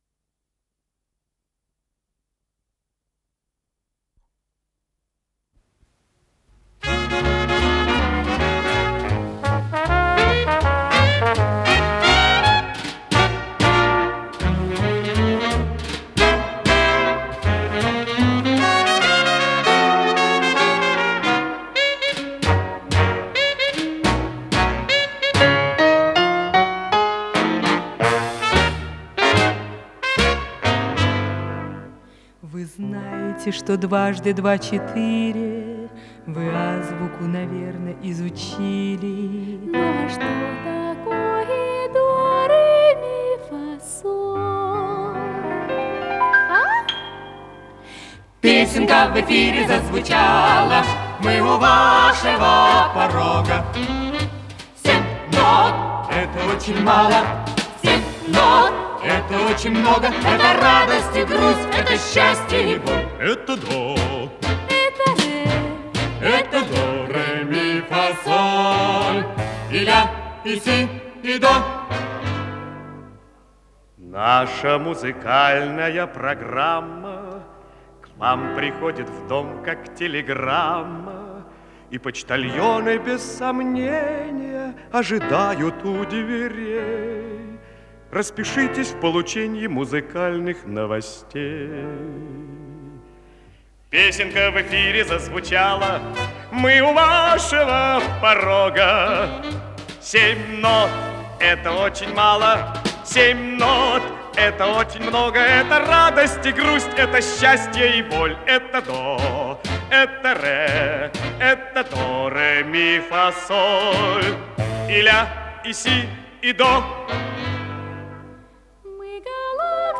с профессиональной магнитной ленты
АккомпаниментИнструментальный ансамбль
ВариантДубль моно